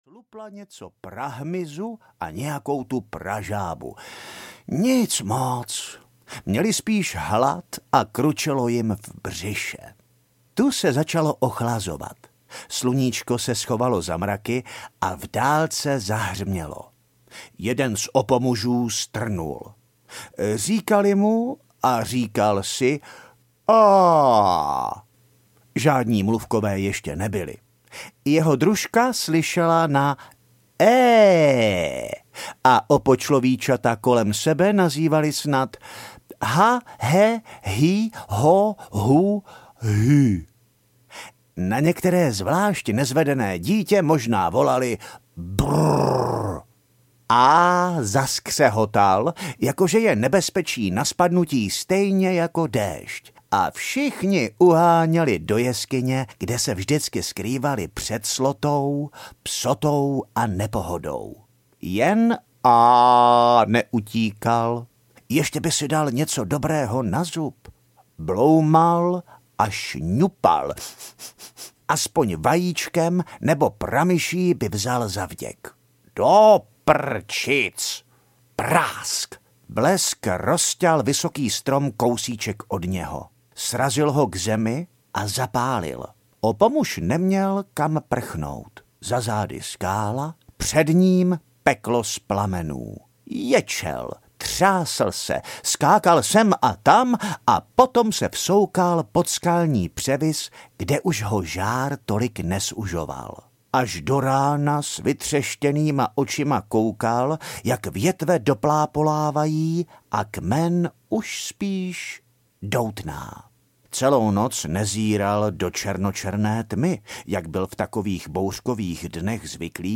Příběhy o vynálezech audiokniha
Ukázka z knihy